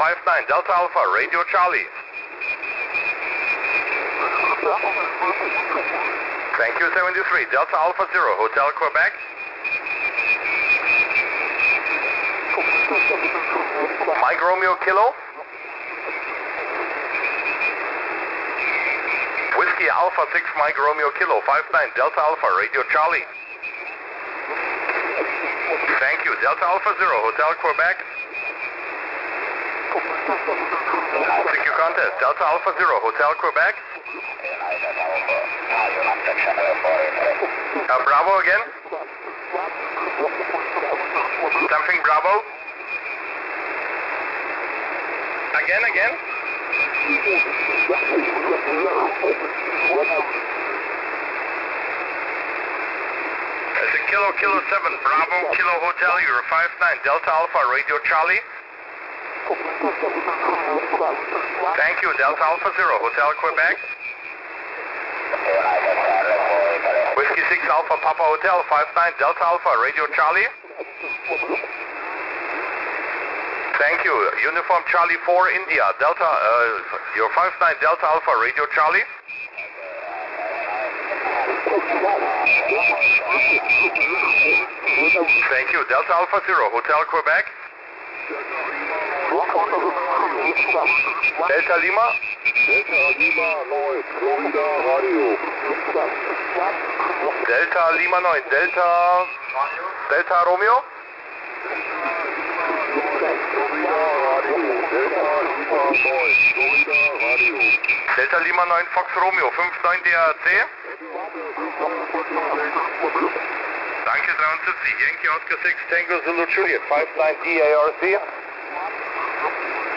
[CW/SSB] [160 – 10m]
⇓ FINNLAND ⇓